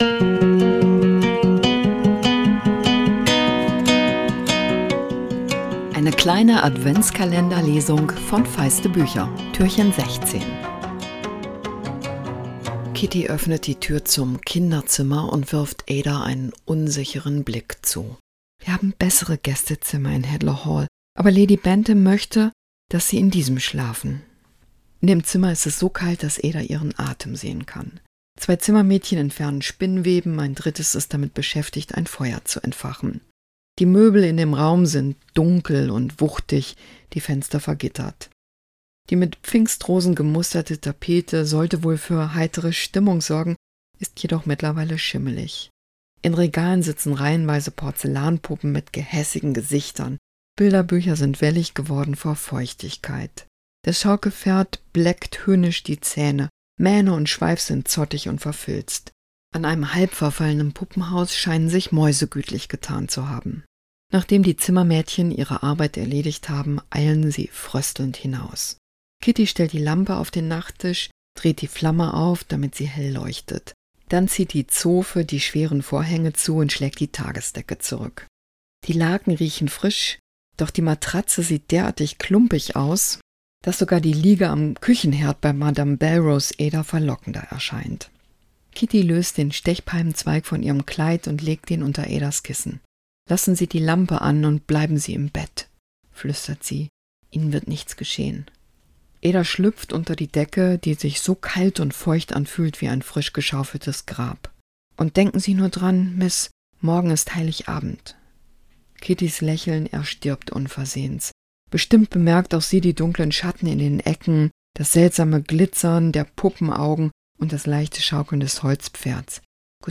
Feiste Bücher 117-16, Adventslesung 2024: "Ada Lark" von Jess Kidd, aus: "Wintergeister"
Adventskalender-Lesung 2024!